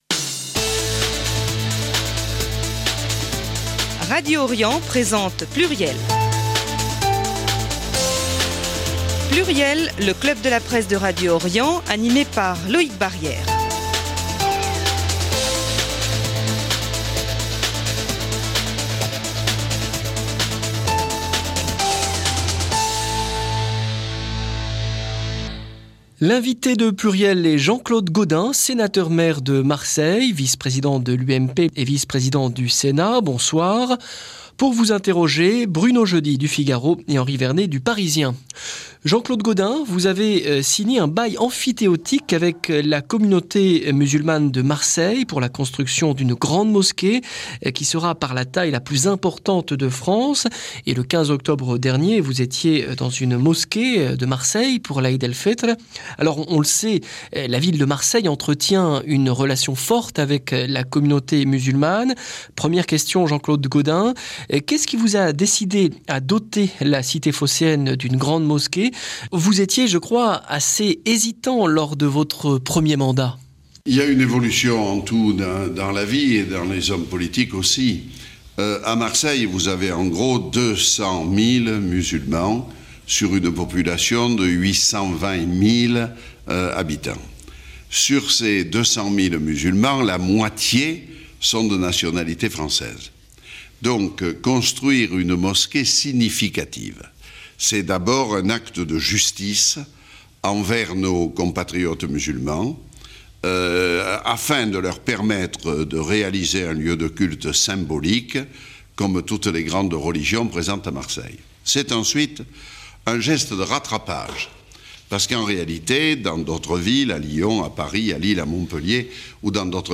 Emission rediffusée en mémoire de l'ancien maire de Marseille
ARCHIVE RADIO ORIENT : En 2007, Jean-Claude Gaudin avait reçu l'équipe de PLURIEL au Sénat.